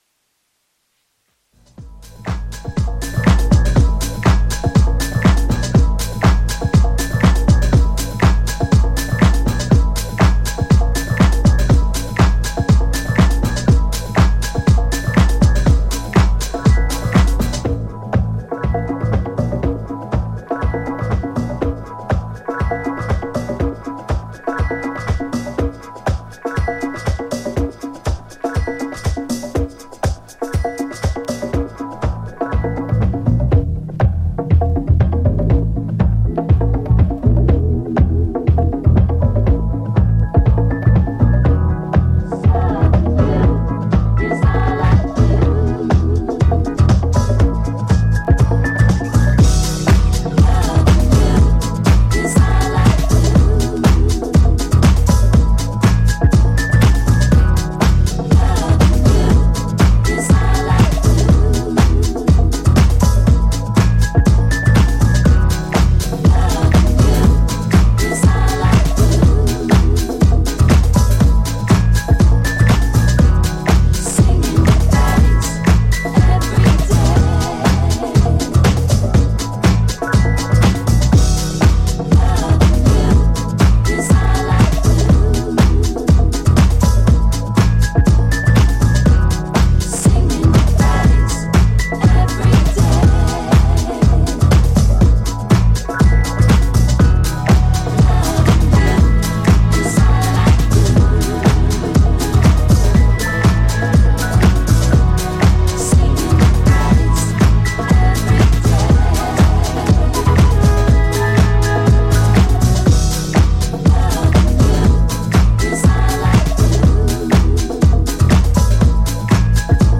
ジャンル(スタイル) DISCO / NU DISCO